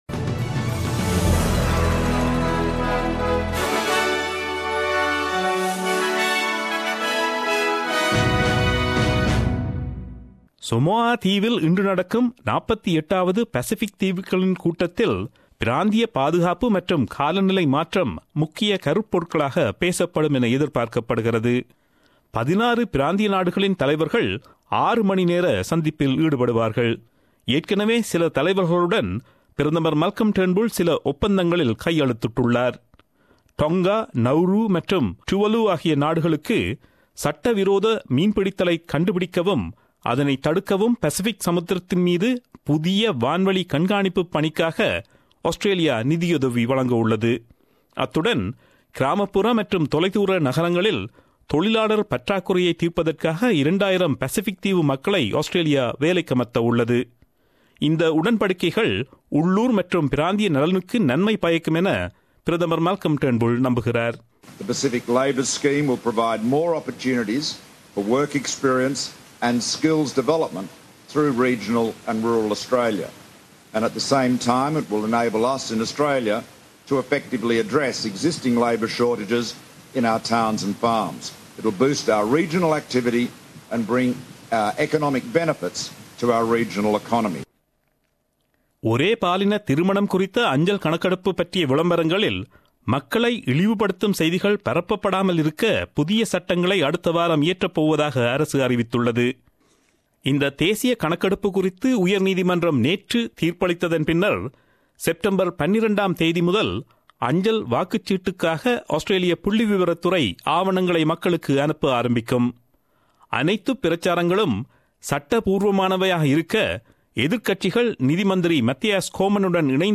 Australian news bulletin aired on Friday 08 September 2017 at 8pm.